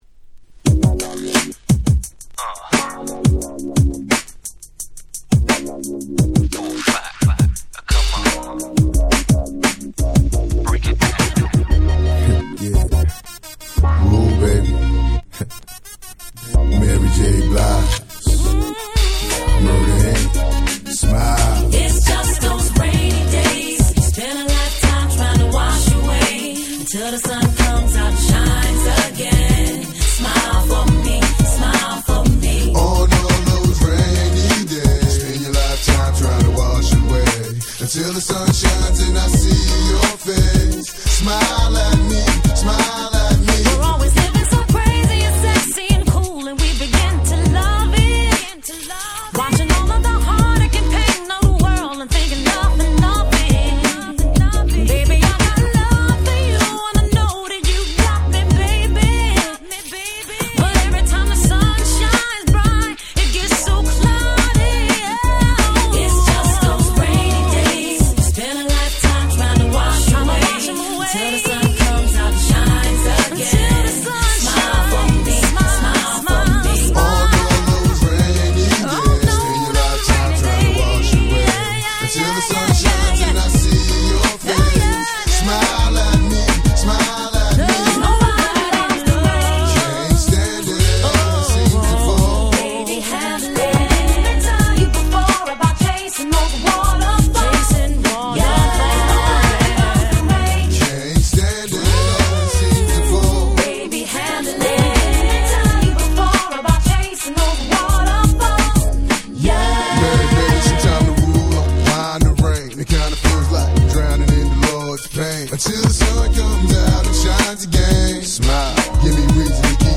02' Smash Hit R&B !!
Beatがビルドアップされたフロア仕様のNiceなRemixです！！